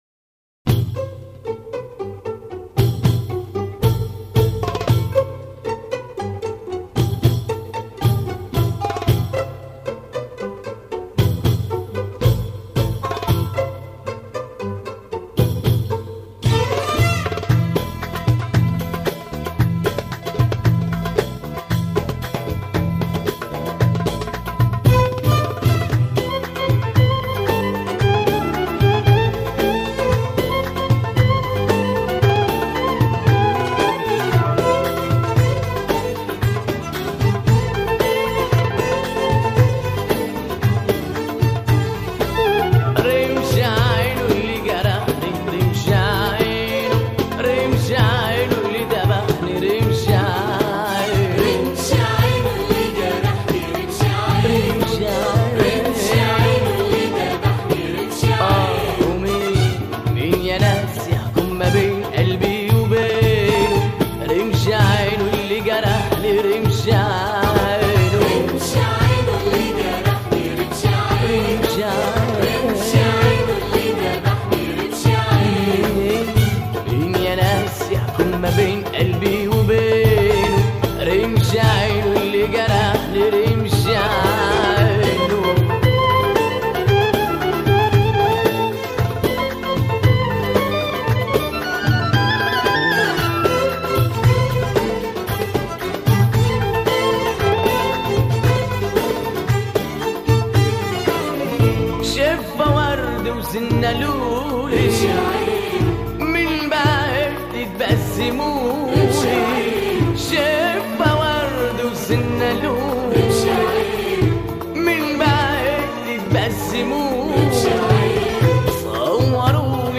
اغاني لبنانيه